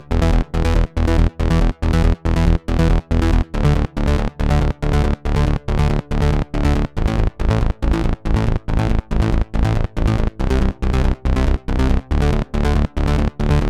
VTS1 Selection Kit Bassline